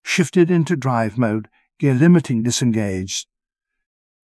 shifted-into-drive.wav